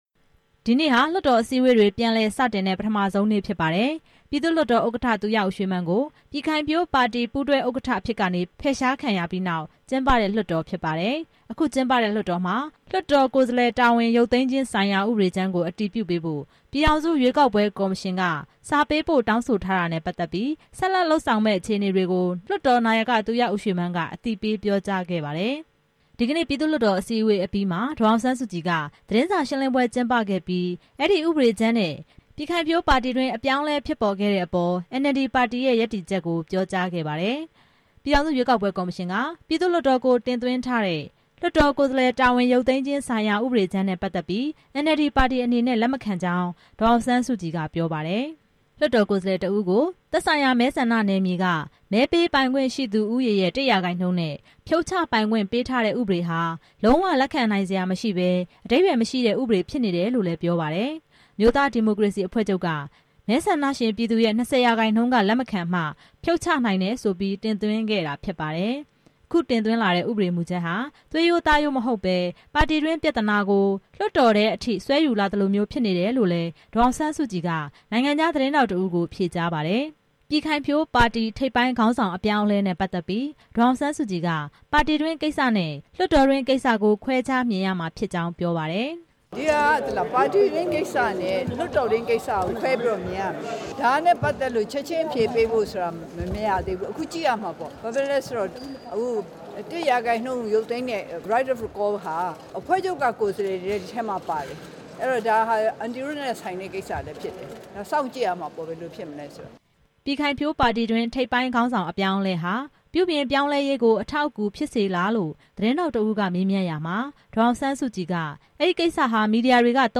ပြည်ထောင်စုရွေးကောက်ပွဲကော်မရှင်က တင်သွင်းထားတဲ့ လွှတ်တော် ကိုယ်စားလှယ်တာဝန် ရုပ်သိမ်းခြင်းဆိုင်ရာ ဥပဒေကြမ်း ကို NLD အနေနဲ့ လက်မခံကြောင်း ဒီနေ့ ပြည်သူ့ လွှတ်တော် အစည်းအဝေးအပြီးမှာ ဒေါ်အောင်ဆန်းစုကြည်က သတင်းစာရှင်းလင်းပွဲကျင်းပပြီး ပြောလိုက်ပါတယ်။